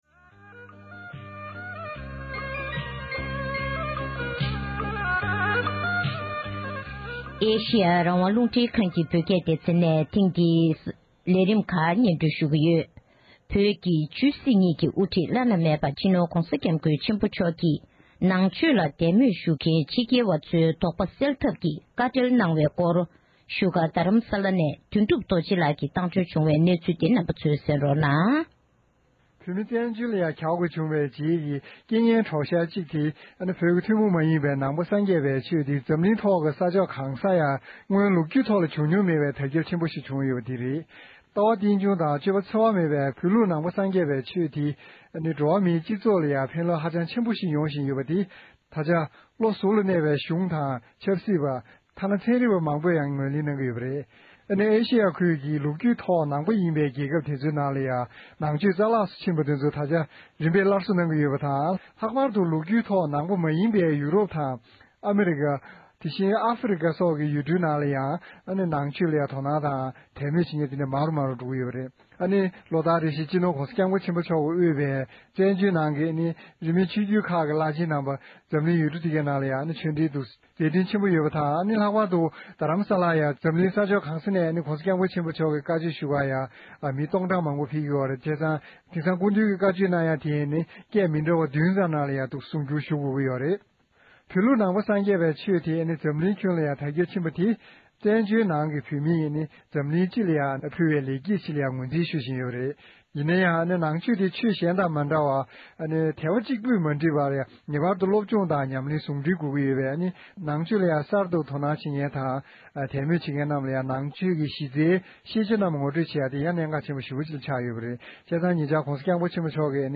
ས་གནས་ནས་བཏང་འབྱོར་བྱུང་བའི་གནས་ཚུལ་ཞིག་ལ་གསན་རོགས༎